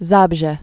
(zäbzhĕ)